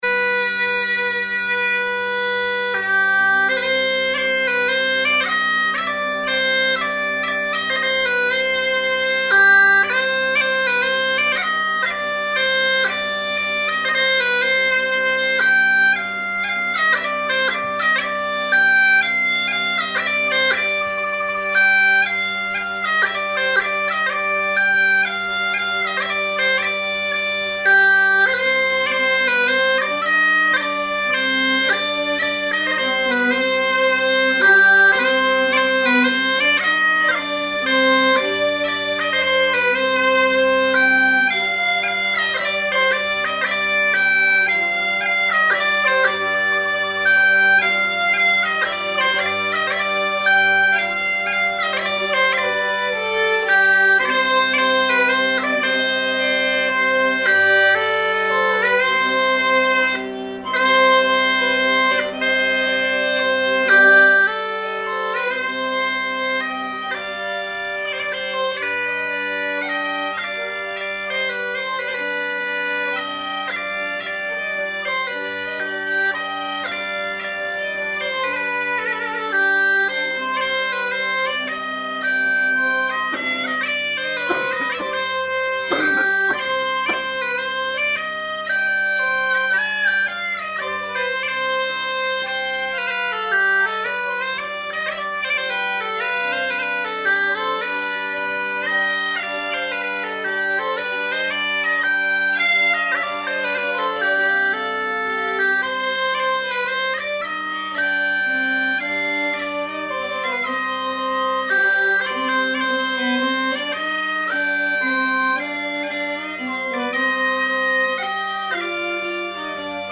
Répétition / Préparation (2019) | Galouvielle